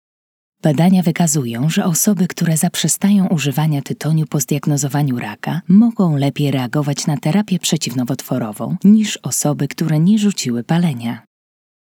All voiceover projects are recorded and mixed by our in-house audio engineers to ensure high fidelity and natural sounding recordings.
Some of our Voice Over Samples
Polish – Female voice
2023-Polish-Female.wav